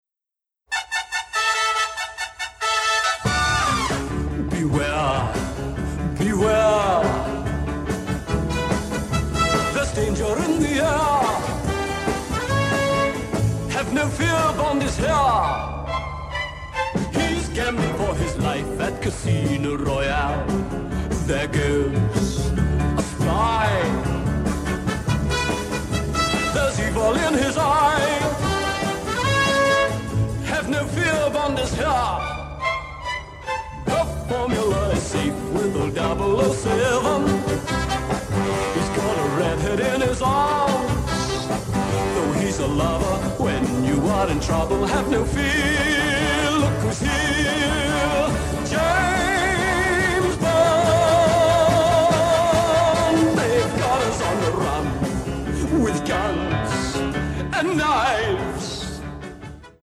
BONUS TRACKS (Mono)